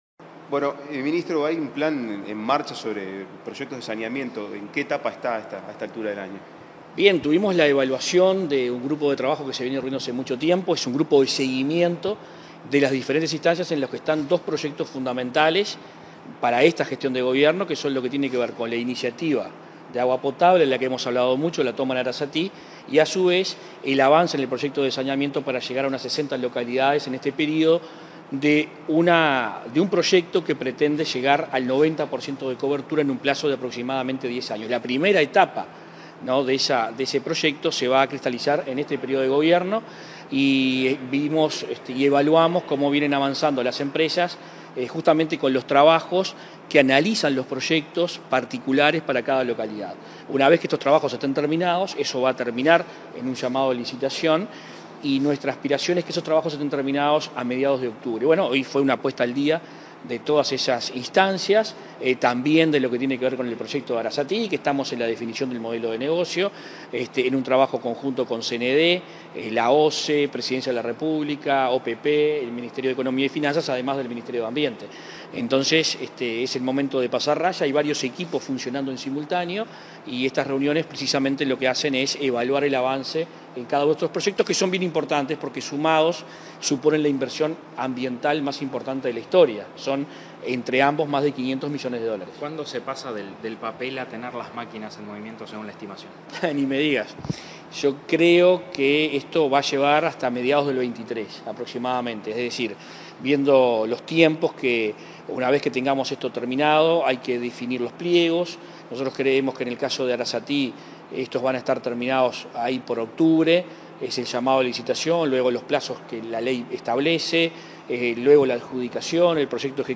Declaraciones del ministro de Ambiente, Adrián Peña
Declaraciones del ministro de Ambiente, Adrián Peña 01/09/2022 Compartir Facebook X Copiar enlace WhatsApp LinkedIn Tras reunirse con el secretario de la Presidencia de la República, Álvaro Delgado, este 31 de agosto, el ministro de Ambiente, Adrián Peña, realizó declaraciones a la prensa.